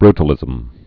(brtl-ĭzəm)